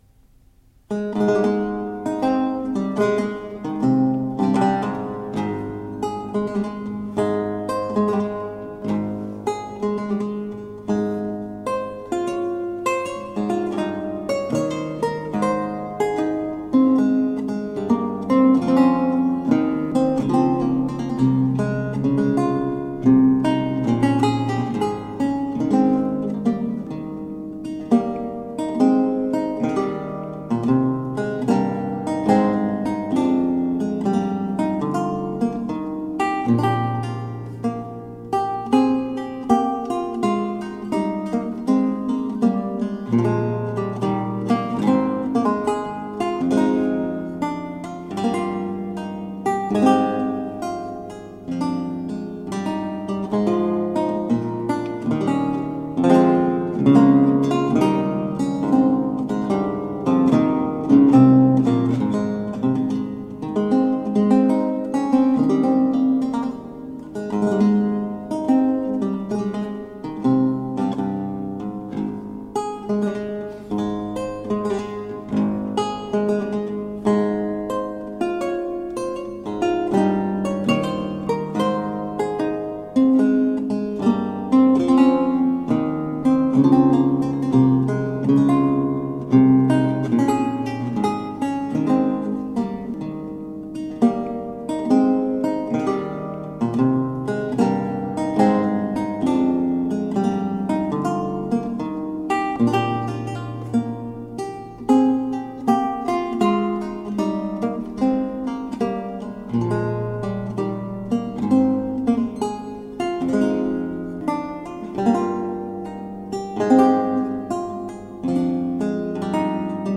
A marvelous classical spiral of lute sounds.